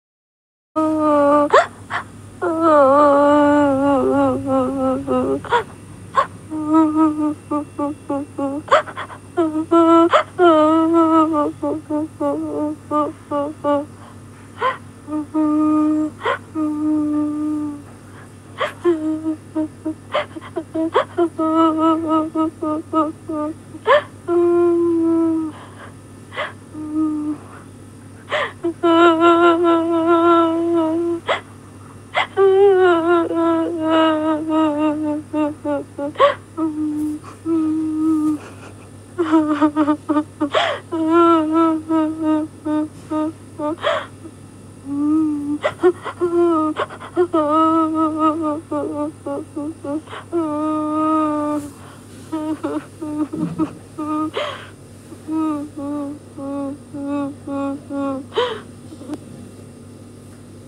Suara Wanita Menangis Tersedu Sedu
Kategori: Efek suara
Keterangan: Download suara wanita menangis tersedu sedu, suara wanita menangis sedih, suara menangis perempuan mp3...
suara-wanita-menangis-tersedu-sedu-id-www_tiengdong_com.mp3